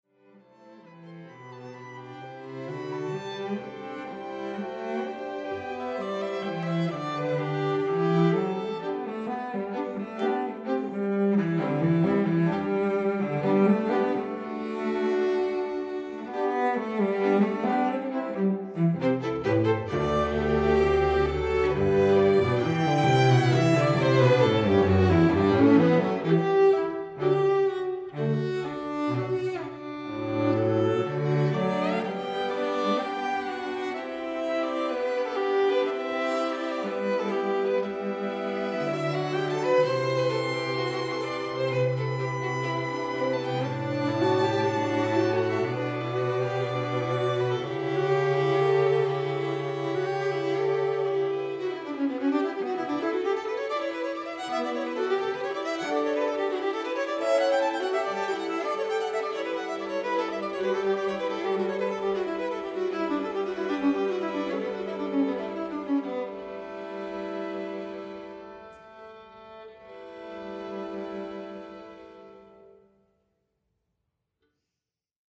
Australian classical music
performed live